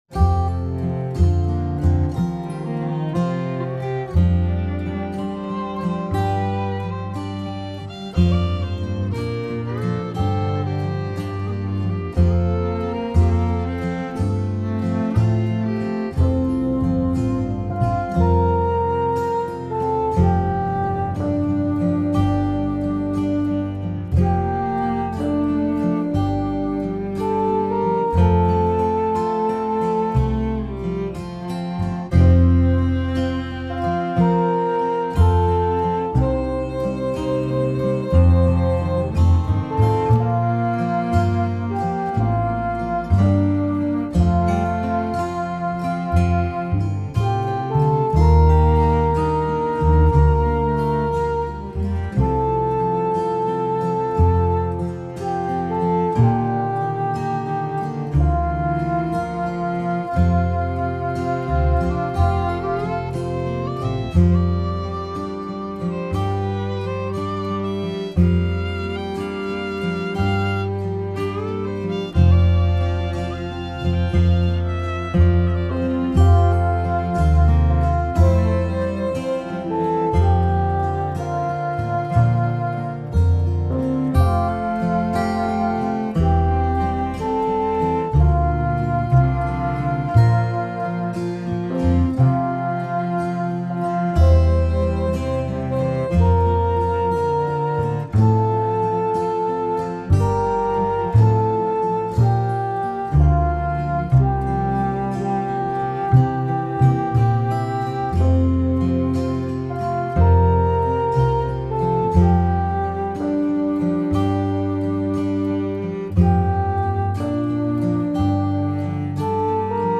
It is very slow but very meditative.